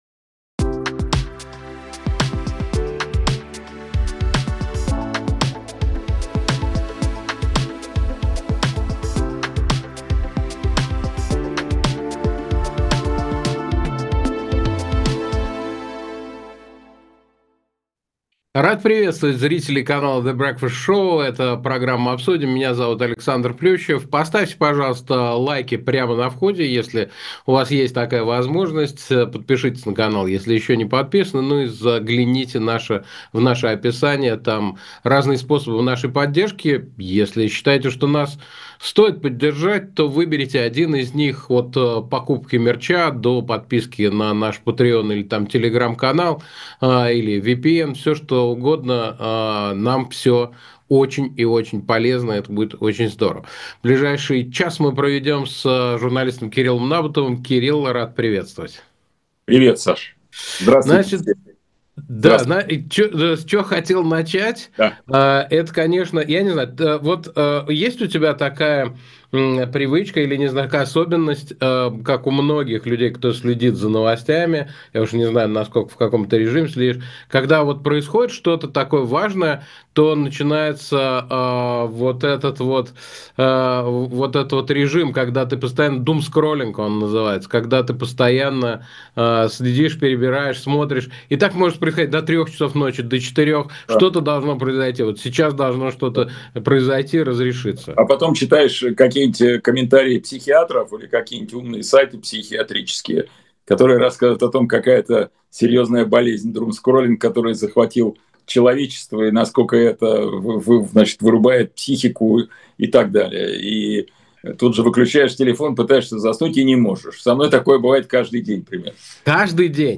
Гость - Кирилл Набутов